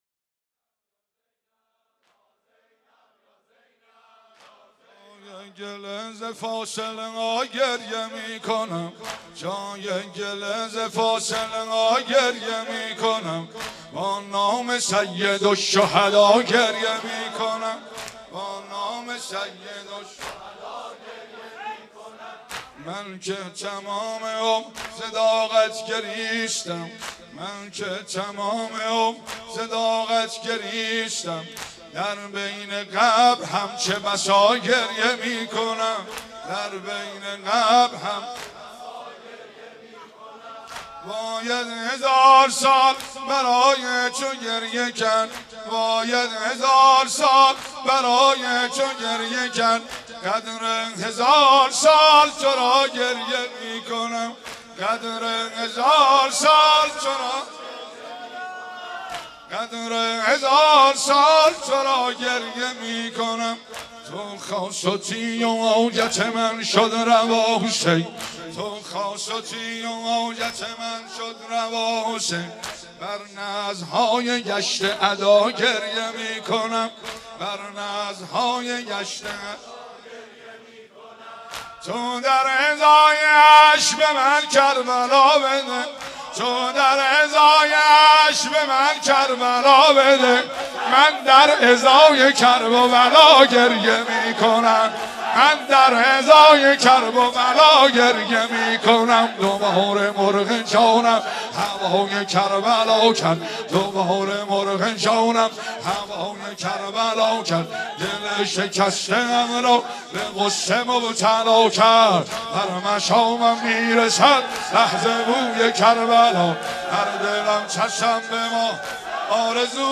بر آل عبا تو نورعینی زینب | شور